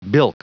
Prononciation du mot bilk en anglais (fichier audio)
Prononciation du mot : bilk